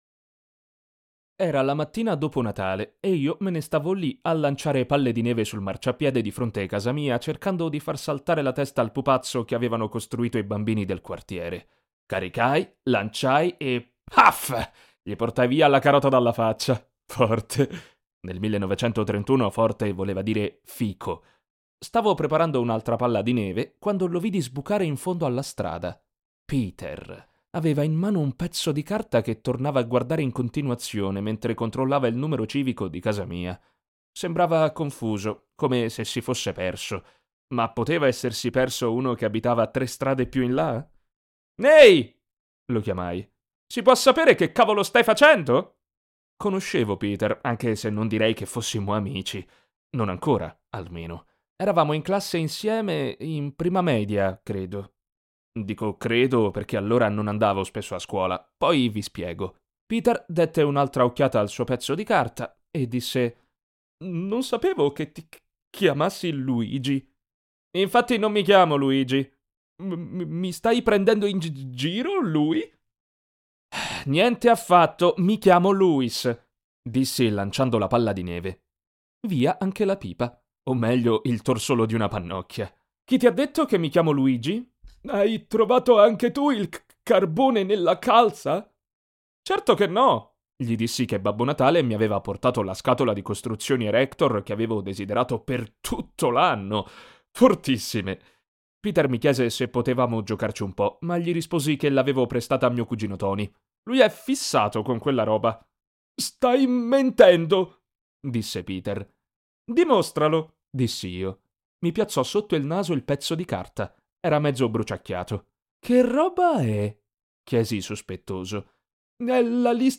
Sprechprobe: Sonstiges (Muttersprache):
Audiolibro.mp3